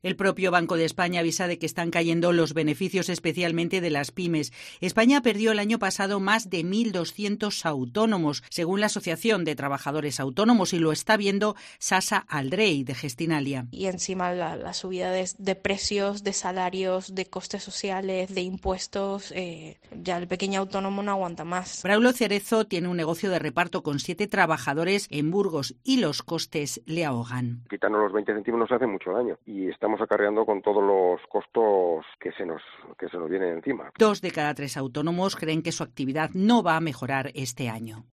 AUDIO: Escucha la crónica